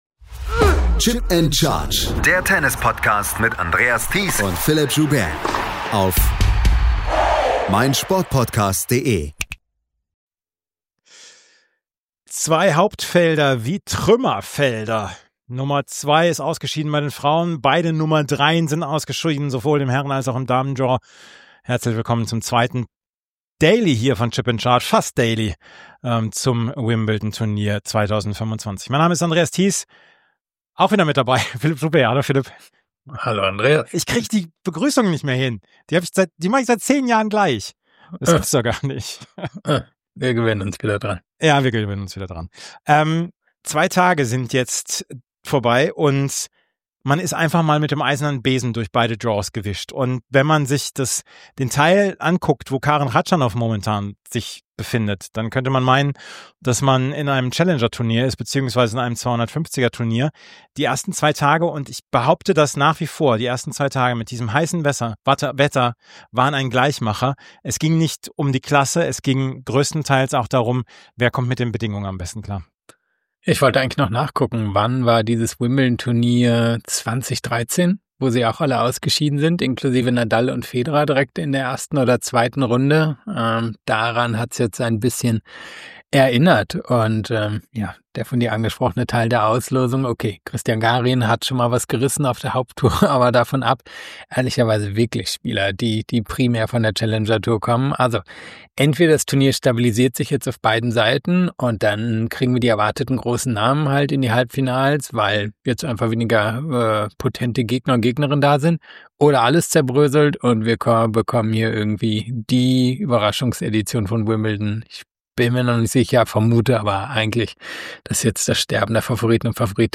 Beschreibung vor 9 Monaten Willkommen zur neuen Ausgabe von Chip & Charge – dieses Mal mit der zweiten Tagessendung aus Wimbledon.